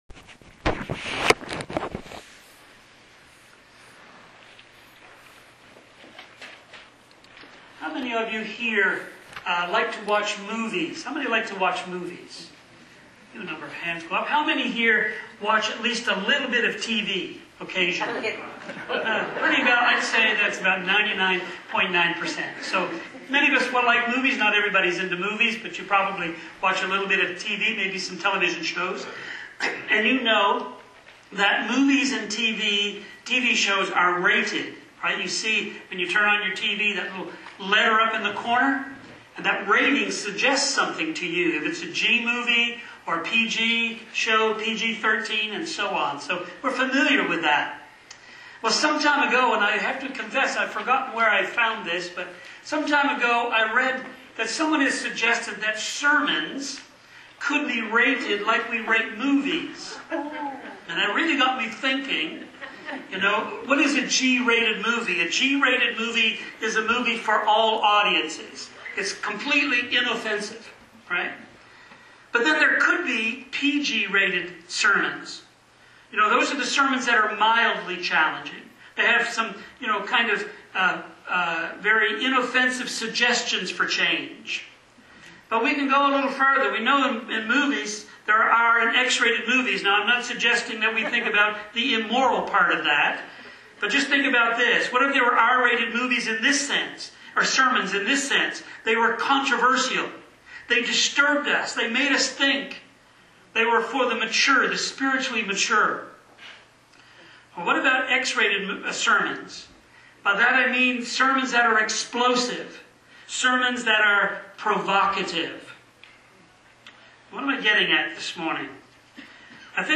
Most Famous Sermon in the World – The Sermon on the Mount Series